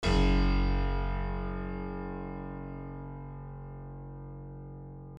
piano-sounds-dev
HardPiano